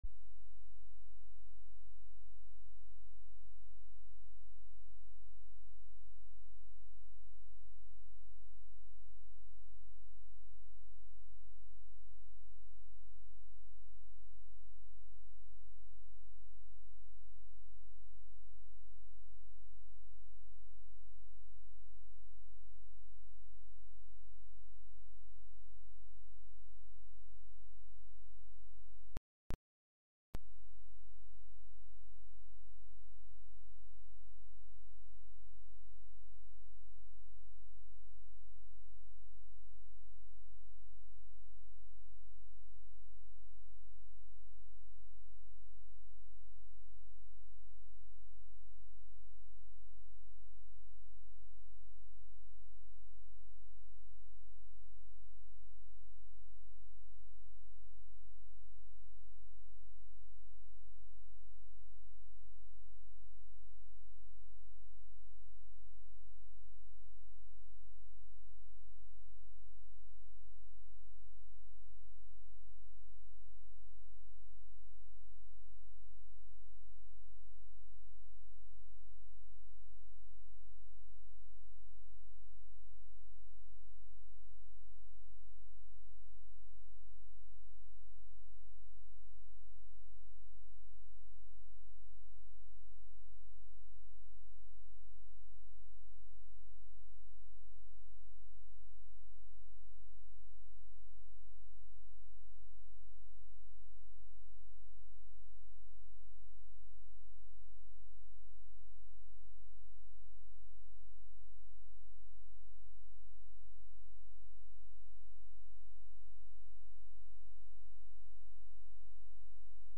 Sermons | Grace Lutheran Church
Lenten-Service-3-13-24.mp3